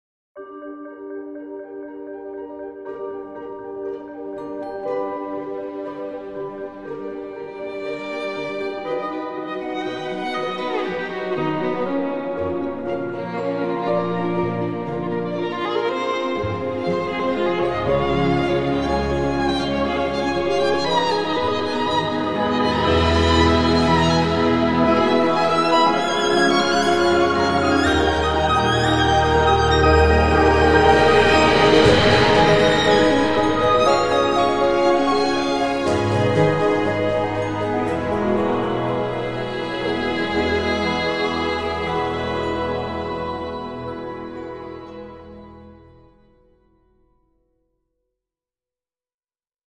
连奏、延音、断奏、短奏、颤音、颤音、和声……我们录制了您对小提琴独奏所期望的一切。
这个房间对我们来说是完美的空间，因为它为声音提供了足够的空间来发挥作用，而不会影响声音，也不会产生不必要的反射。